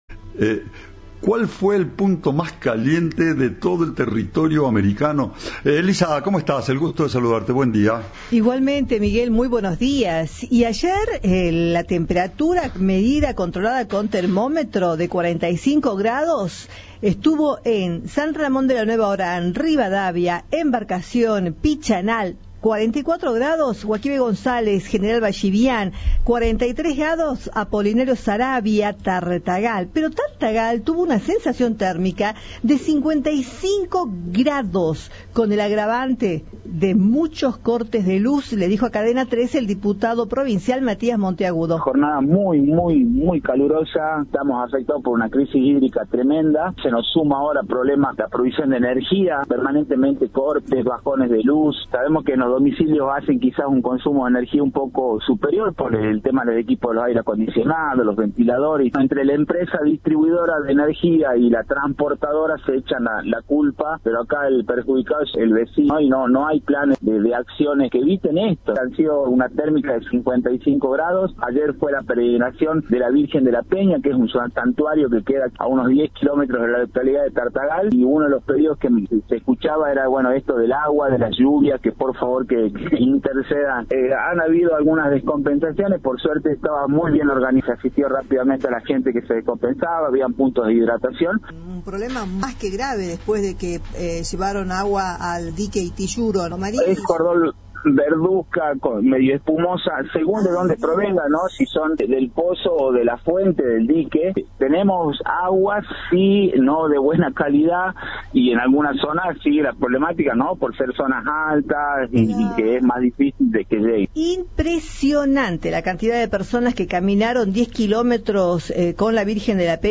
Al respecto, el diputado provincial Matías Monteagudo dijo a Cadena 3 que se vivió una jornada muy calurosa y que la provincia atraviesa una "crisis hídrica tremenda".
Informe